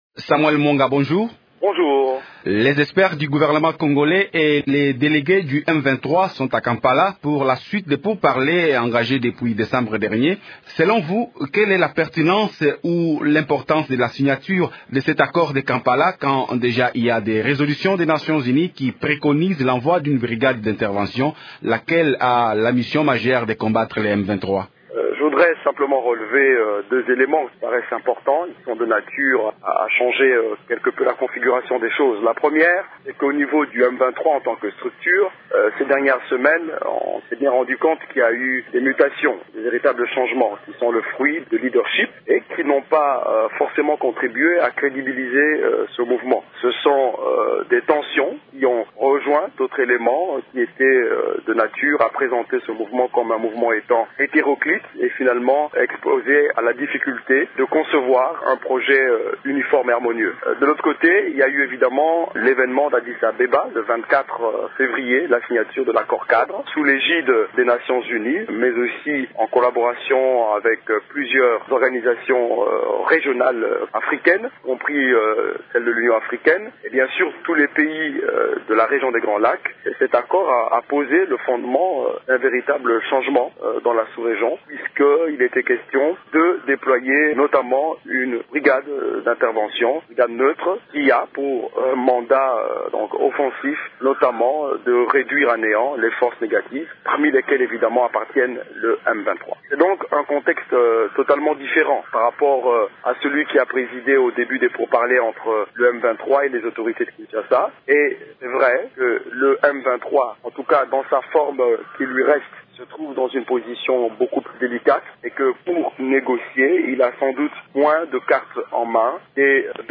«Le M23, dans sa forme qui lui reste, se trouve dans une position beaucoup plus délicate et pour négocier, il n’a plus de cartes en mains. Donc il est dos au mur», indique-t-il dans cette interview.